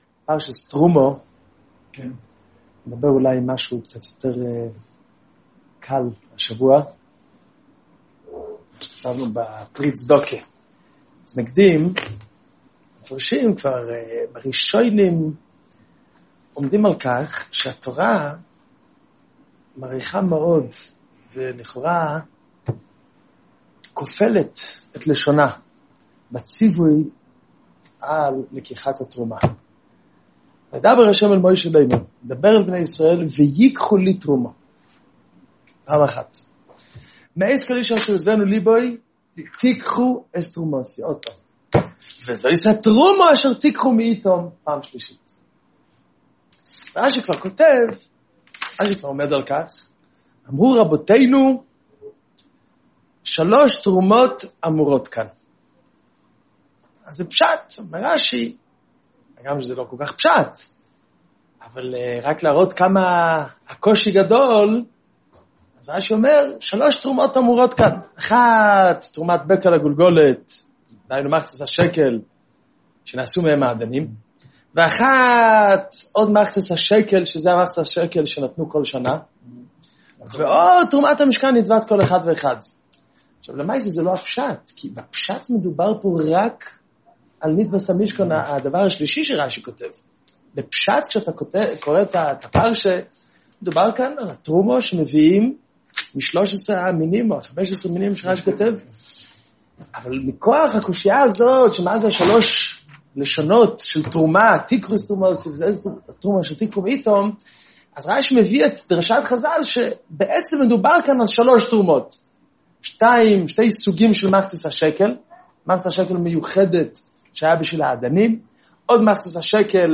שיעור תרומה תשע”ז + שיעור תרומה תשע”ז-הוספה – זהב וכסף ונחושת – שלושת הדרגות של נותני צדקה